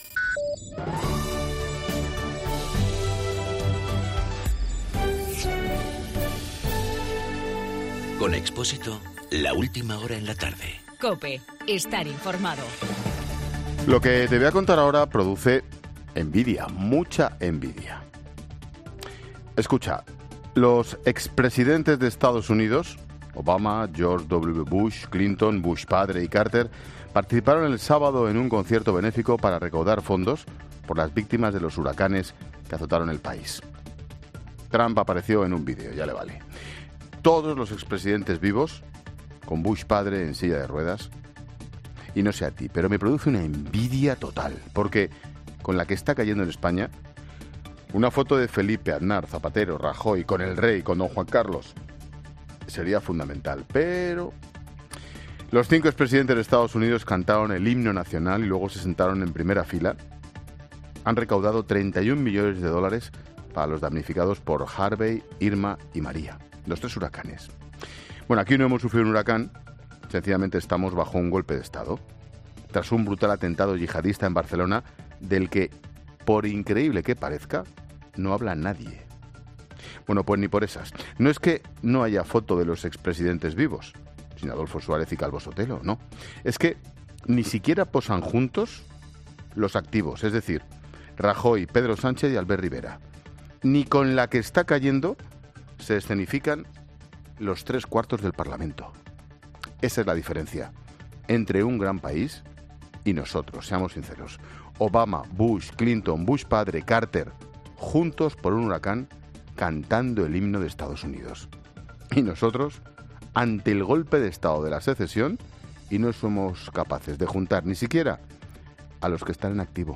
AUDIO: El comentario de Ángel Expósito.
Monólogo de Expósito